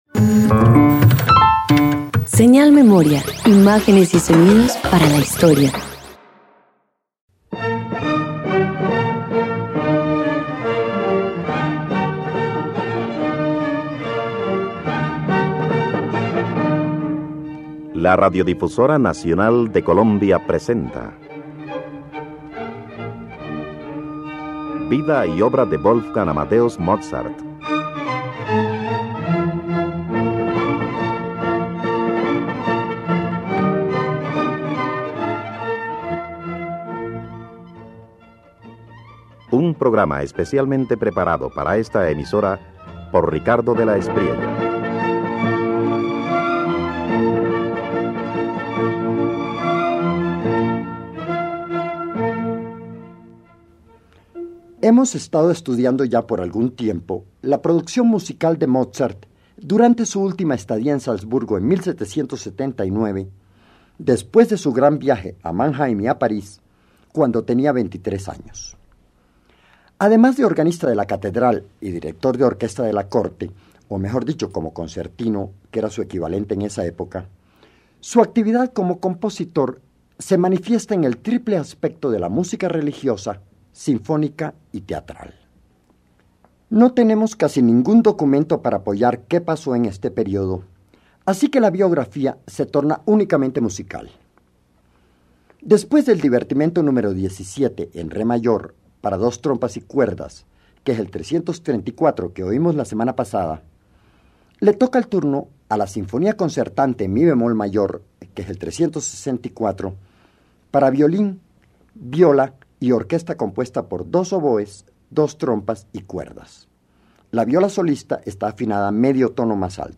Mozart profundiza su periodo en Salzburgo con la Sinfonía Concertante en mi bemol mayor K364, una obra de gran aliento que une violín y viola en un diálogo equilibrado, donde la nobleza, la intimidad y la energía se funden con maestría.
Radio colombiana